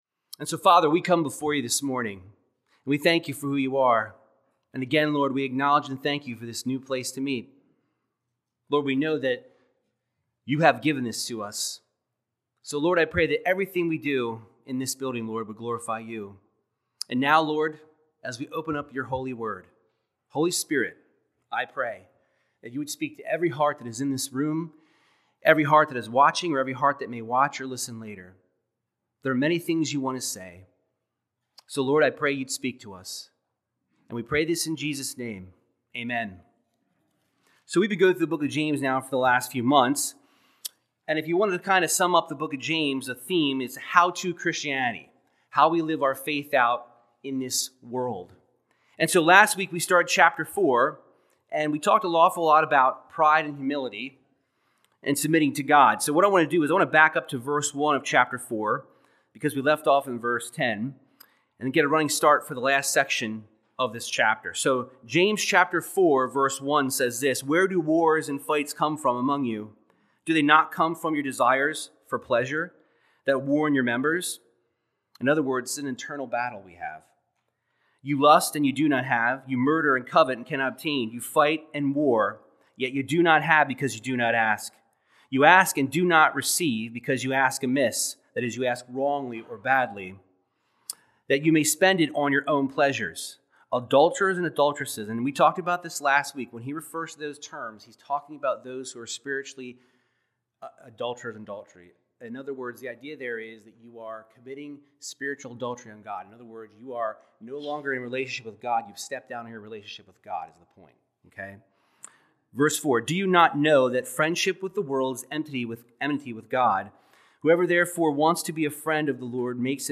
Verse by verse Bible teaching of James 4:11-17 discussing how we should walk in humility, and not tear others down or boast about ourselves.